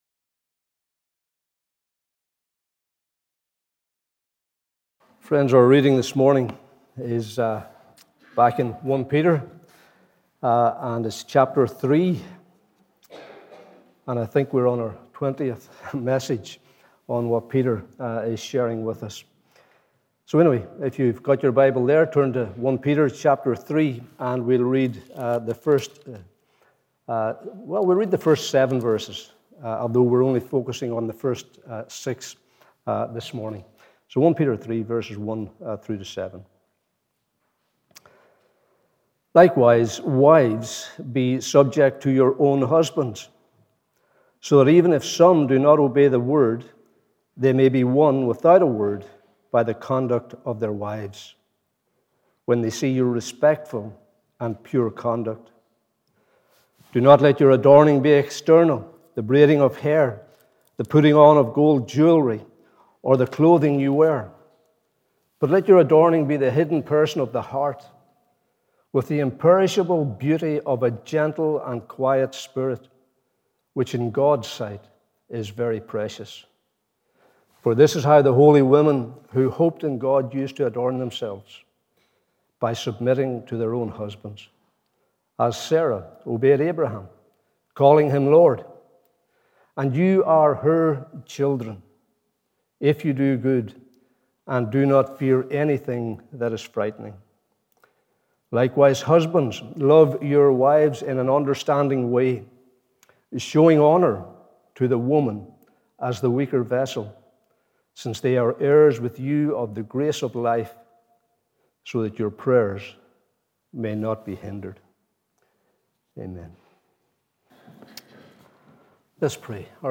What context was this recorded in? Morning Service 27th March 2022